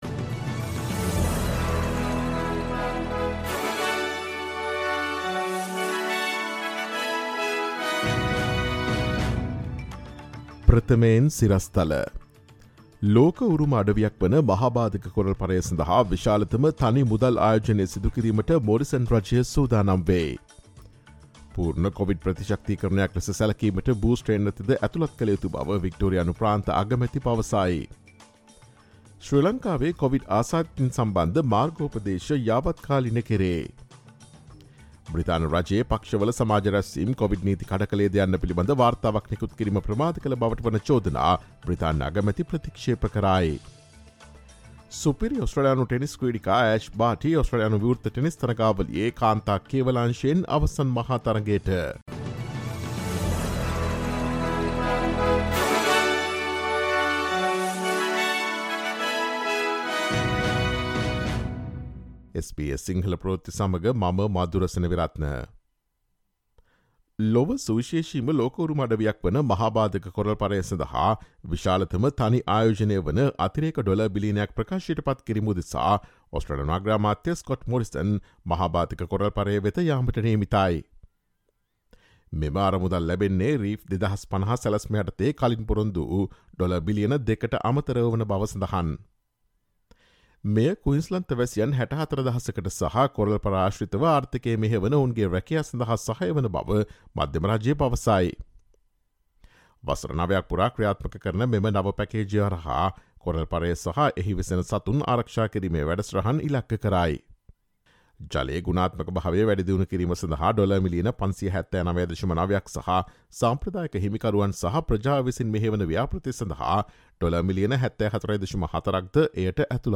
sinhala_news_final_-_jan_28.mp3